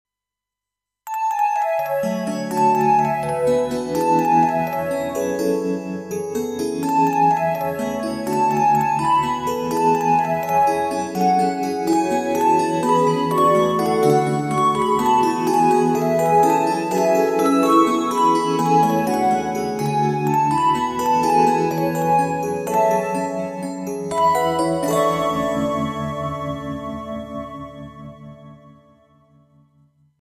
フリー着信音
「址」のメインモチーフをアレンジしたものです。